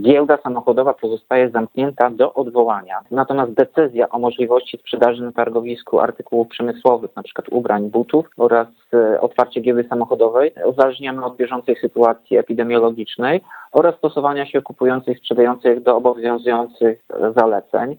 Targowisko będzie funkcjonowało w ograniczonym zakresie – dodaje prezydent Ełku.